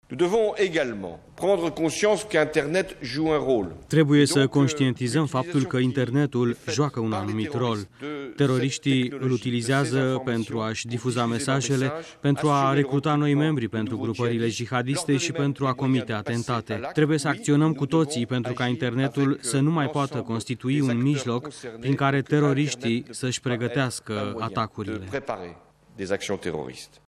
Preşedintele francez Francois Hollande a afirmat astăzi că ameninţarea venită din partea islamiştilor necesită un răspuns colectiv ferm şi a cerut ţărilor europene să instituie măsuri mai stricte de supraveghere a frontierelor şi a internetului: